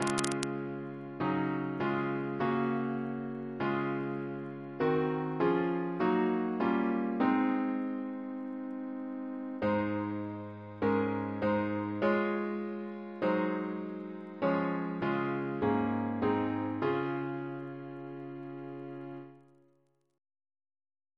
Double chant in D♭ Composer: Philip Armes (1836-1908) Reference psalters: ACB: 102